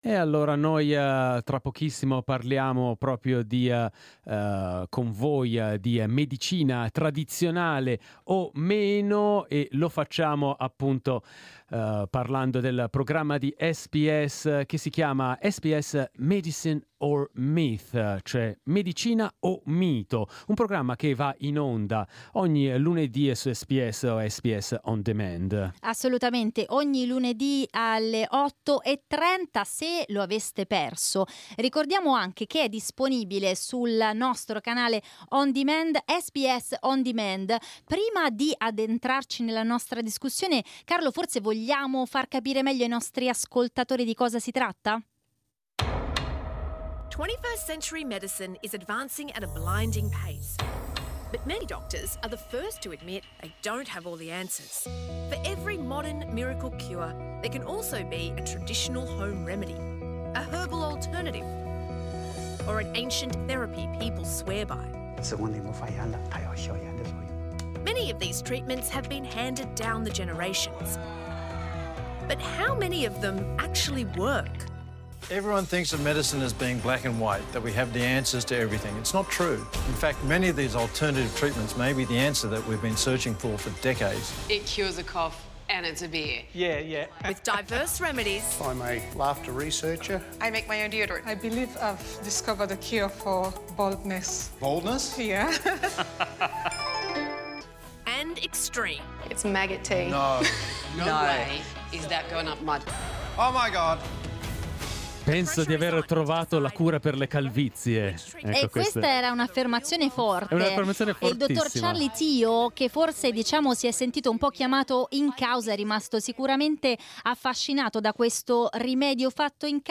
talkback_remedies_0.mp3